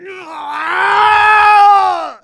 кричит что есть мочи.
Spy_paincrticialdeath01_ru.wav